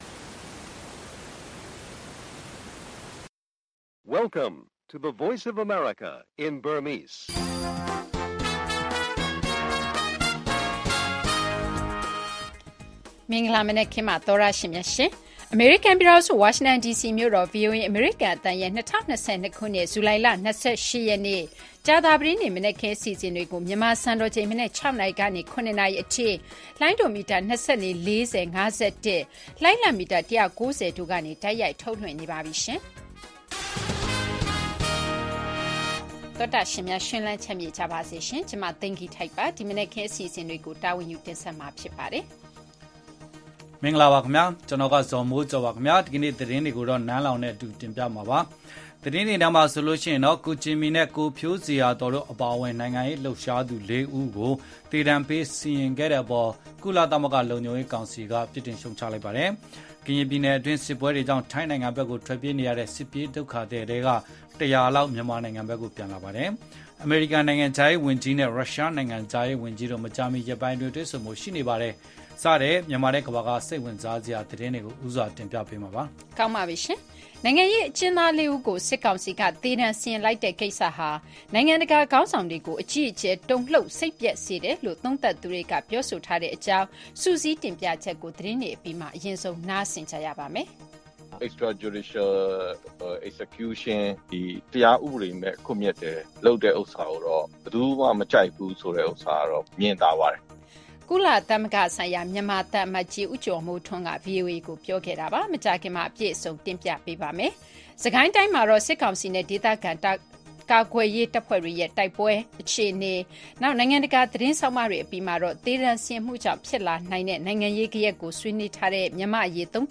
ဗွီအိုအေ နံနက်ခင်း ရေဒီယို အစီအစဉ် ဇူလိုင် ၂၈၊ ၂၀၂၂။